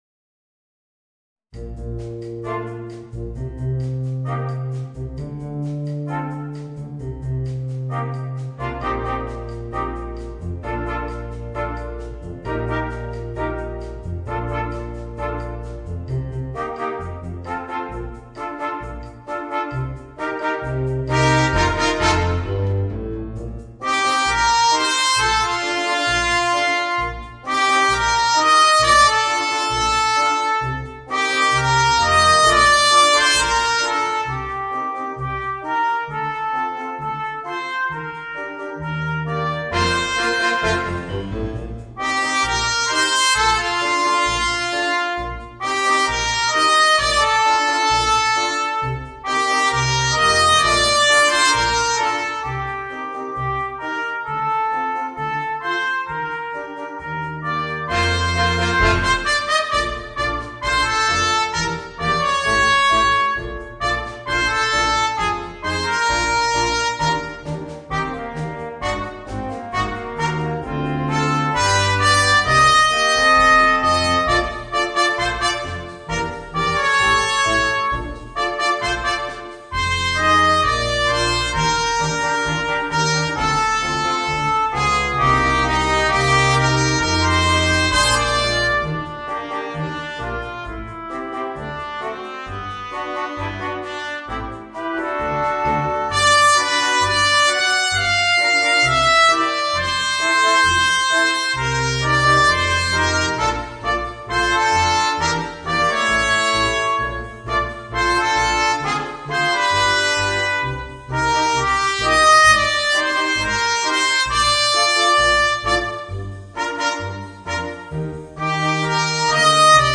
Voicing: 4 - Part Ensemble